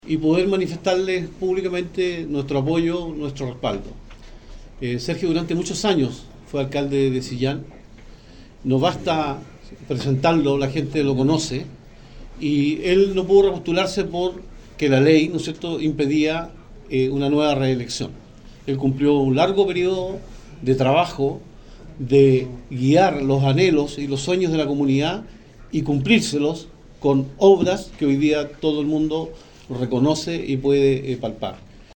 Por su parte, Claudio Alvarado, también exsenador por la región y exministro de la Secretaría General de la Presidencia, reforzó el mensaje de unidad y compromiso de Chile Vamos en torno a la candidatura de Zarzar.